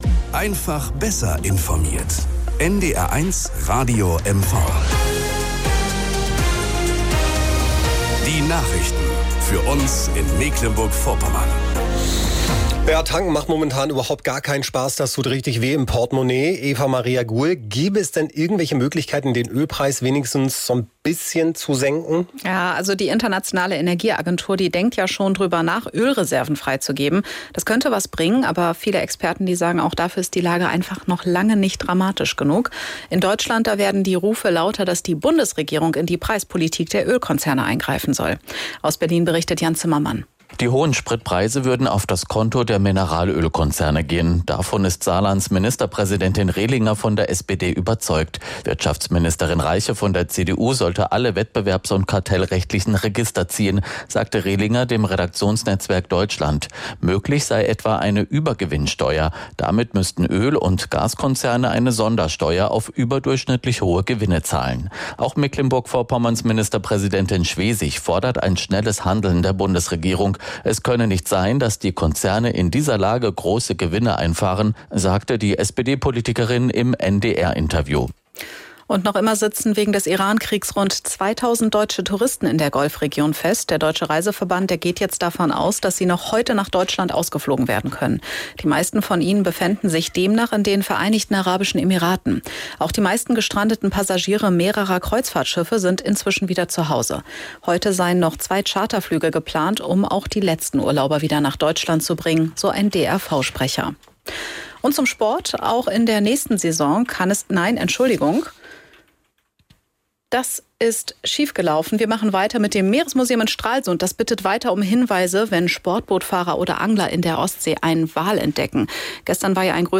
Nachrichten und Informationen aus Mecklenburg-Vorpommern, Deutschland und der Welt von NDR 1 Radio MV.